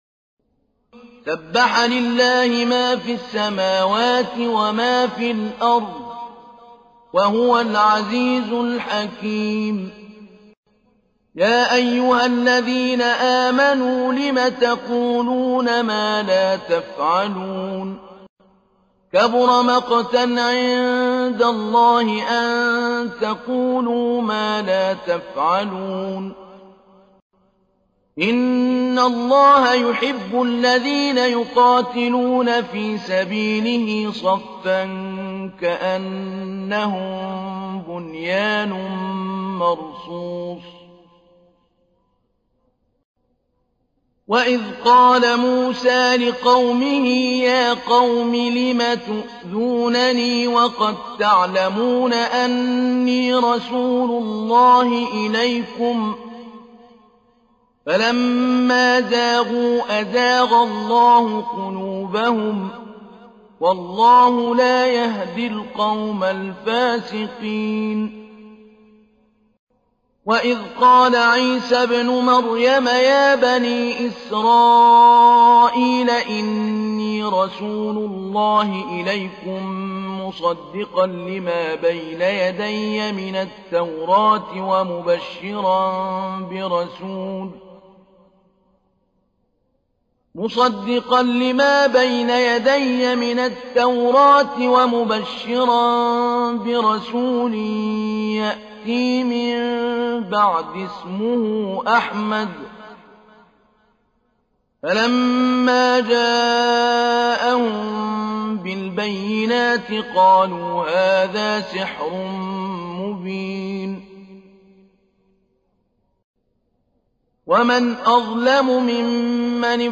تحميل : 61. سورة الصف / القارئ محمود علي البنا / القرآن الكريم / موقع يا حسين